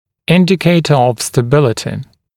[‘ɪndɪkeɪtə əv stə’bɪlətɪ][‘индикейтэ ов стэ’билэти]показатель стабильности